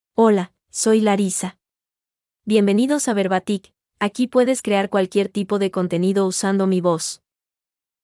FemaleSpanish (Mexico)
Larissa — Female Spanish AI voice
Larissa is a female AI voice for Spanish (Mexico).
Voice sample
Female
Larissa delivers clear pronunciation with authentic Mexico Spanish intonation, making your content sound professionally produced.